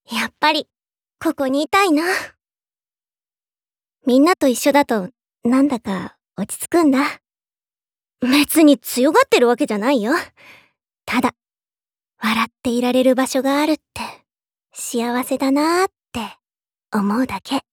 ボイスサンプル4